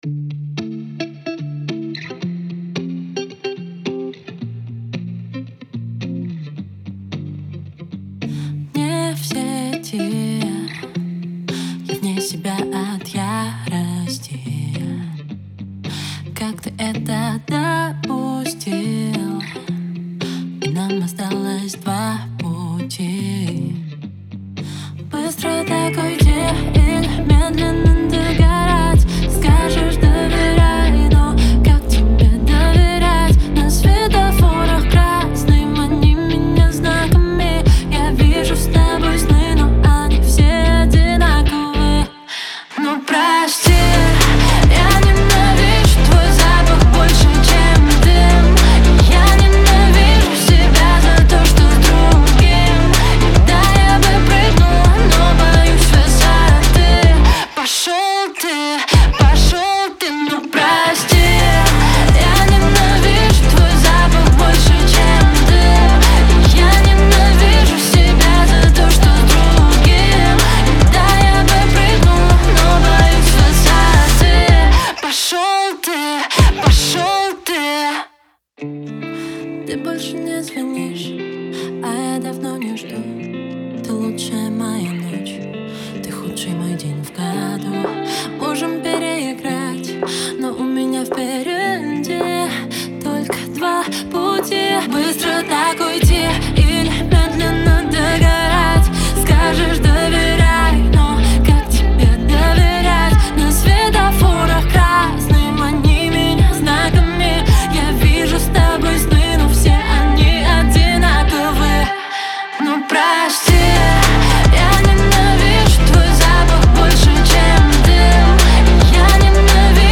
это мощная композиция в жанре поп-рок